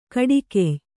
♪ kaḍikey